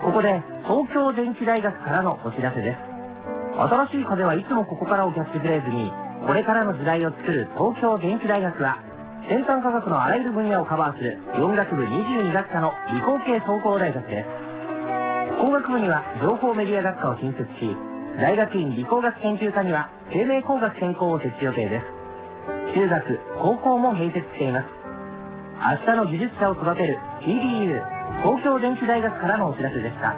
tdu_radiocm.wav